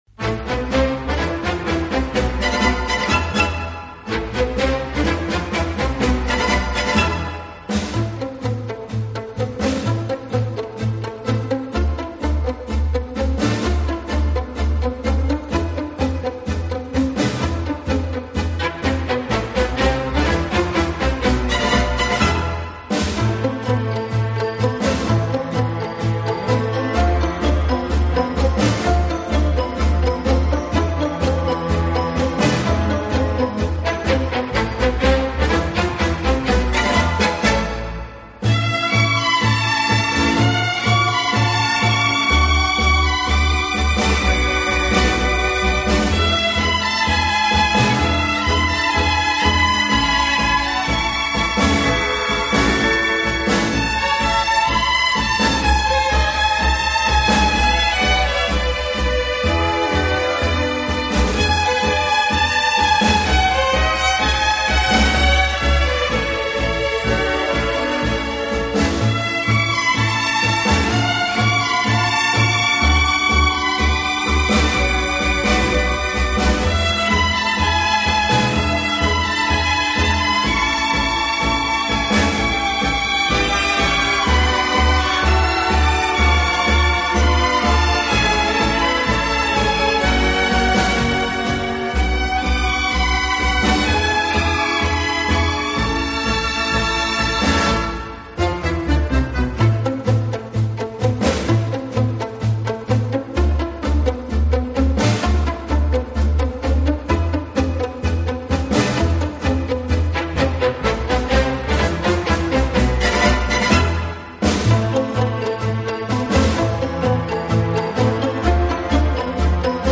【轻音乐】
乐背景的不同，以各种乐器恰到好处的组合，达到既大气有力又尽显浪漫的效果。
乐队的弦乐柔和、优美，极有特色，打击乐则气度不凡，而手风琴、钢琴等乐器